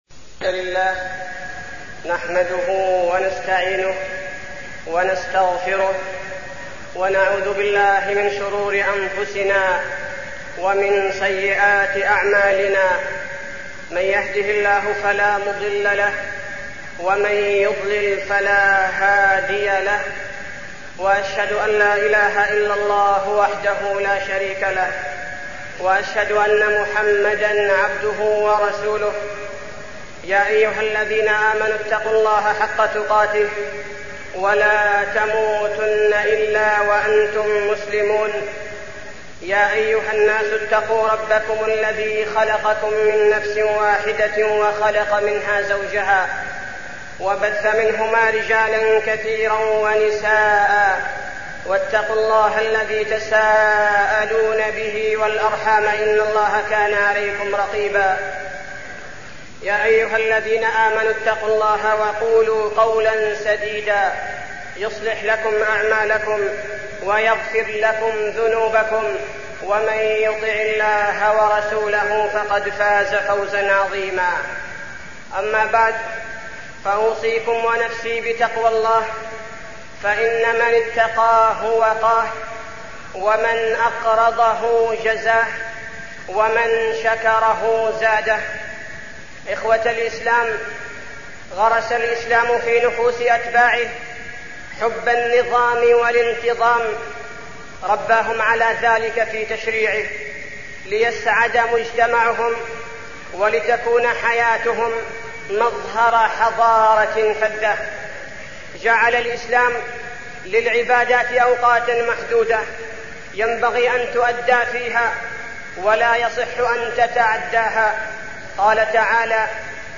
تاريخ النشر ١٤ شوال ١٤١٧ هـ المكان: المسجد النبوي الشيخ: فضيلة الشيخ عبدالباري الثبيتي فضيلة الشيخ عبدالباري الثبيتي النظام في العبادة